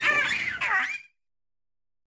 Cri de Tapatoès dans Pokémon Écarlate et Violet.